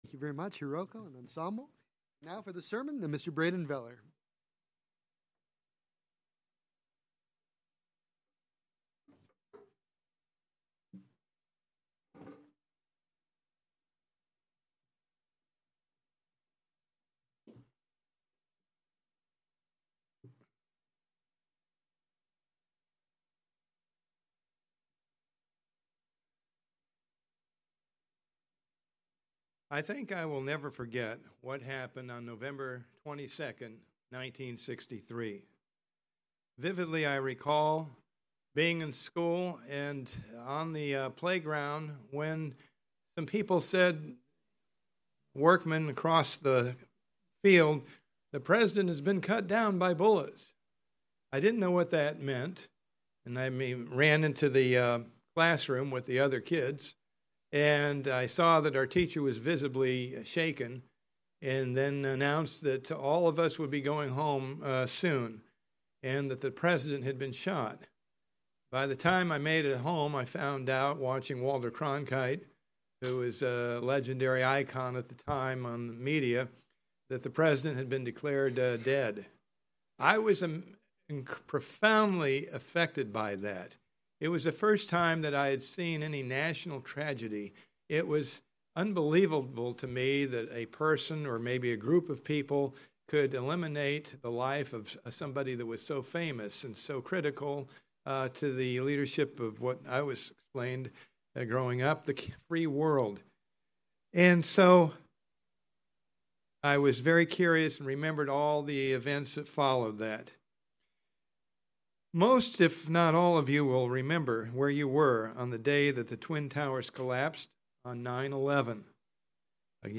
There is going to come a day when we will say I remember what it was like when Christ returned. In this Sermon you will learn what God has built into all of us.